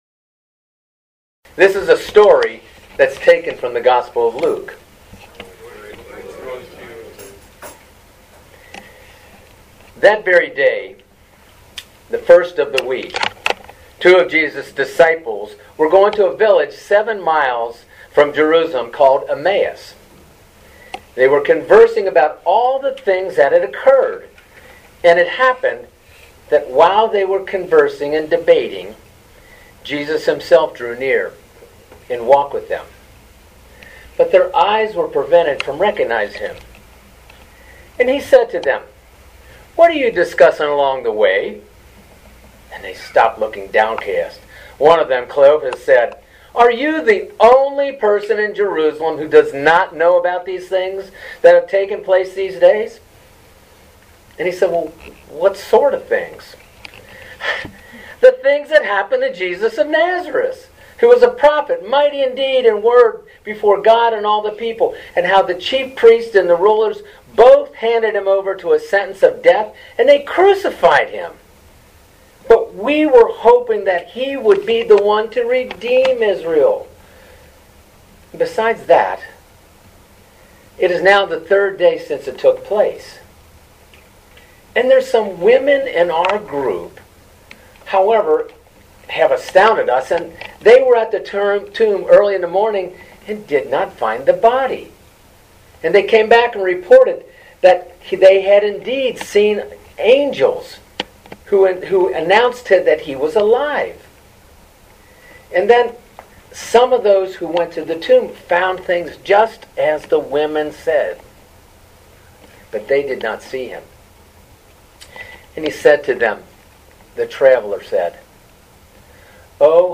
Living Beatitudes Community Homilies: Road to Emmaus